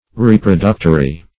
Reproductory \Re`pro*duc"to*ry\ (-t?-r?), a.
reproductory.mp3